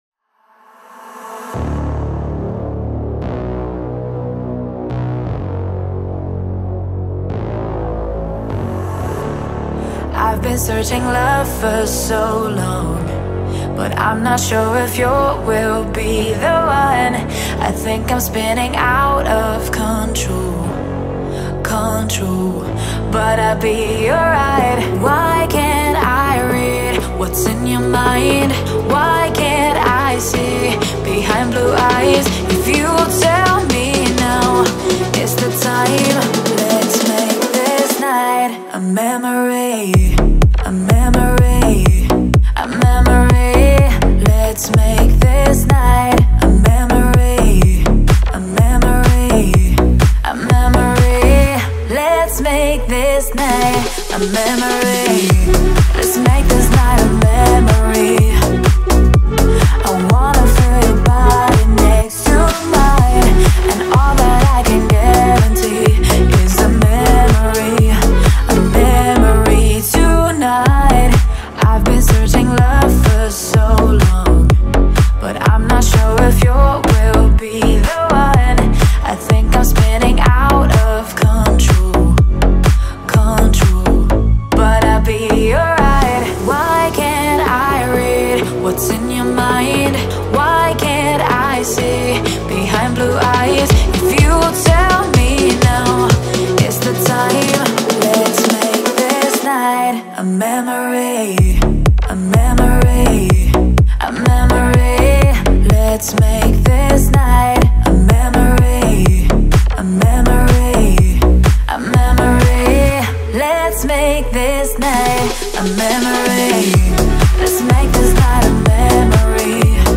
это атмосферная композиция в жанре электронного попа